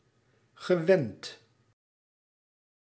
Ääntäminen
Ääntäminen France Tuntematon aksentti: IPA: /a.bi.tɥe/ Haettu sana löytyi näillä lähdekielillä: ranska Käännös Ääninäyte Substantiivit 1. stamgast {m} Muut/tuntemattomat 2. gewoon 3. gewend Suku: m .